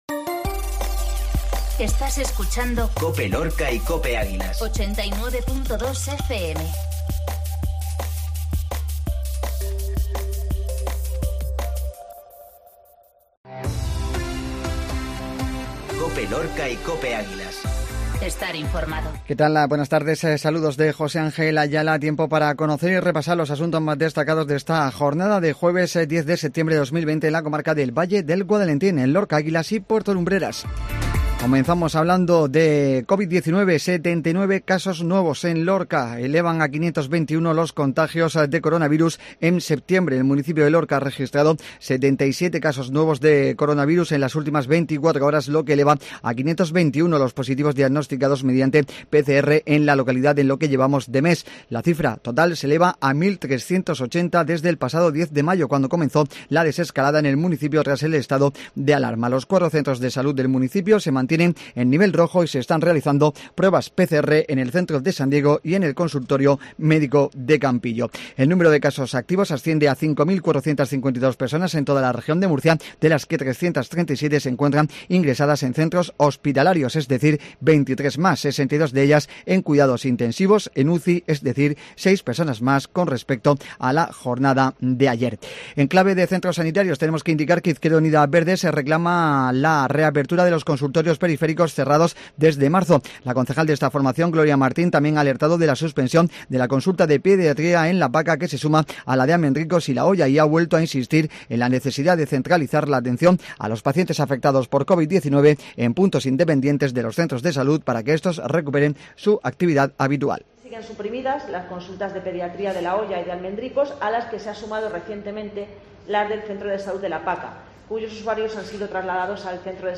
INFORMATIVO MEDIODÍA COPE LORCA 1009